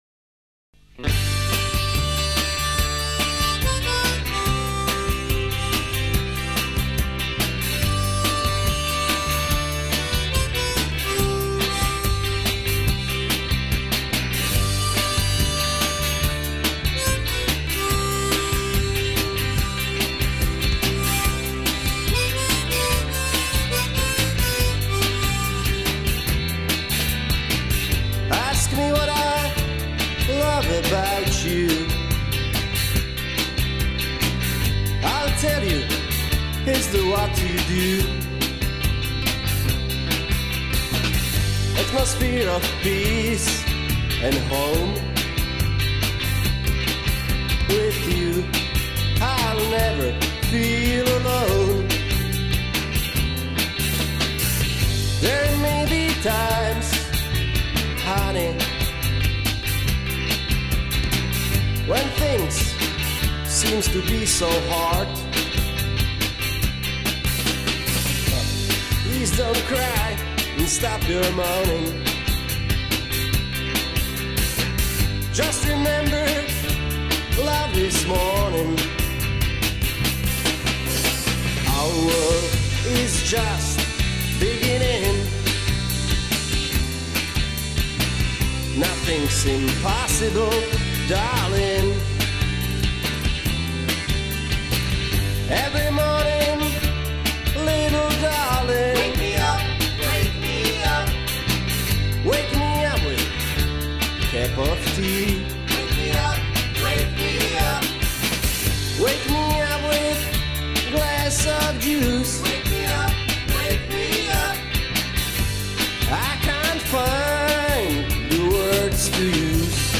lead and rhythm guitar, singer and a harp player
lead/rhytm guitars